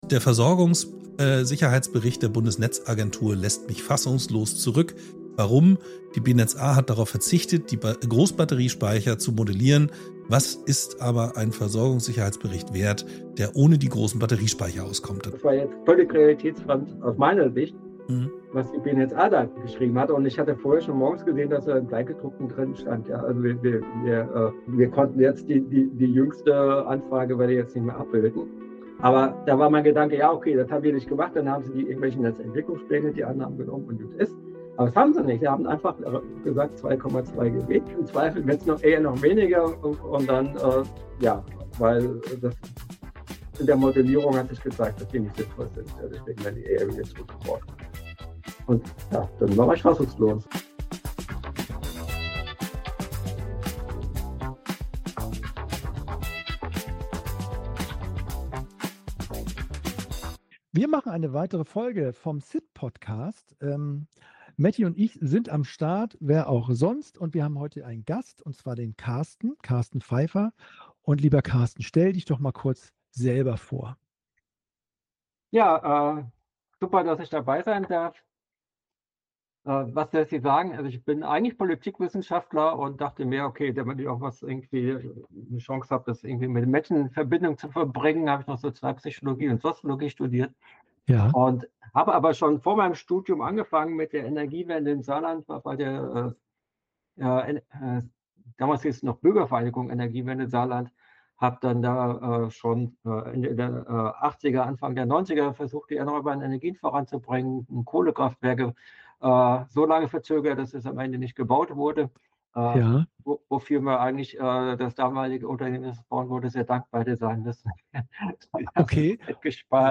Ein Gespräch über Fassungslosigkeit, Fortschritt und die Frage, warum Kurzzeitspeicher unterschätzte Helden der Energiewende sind.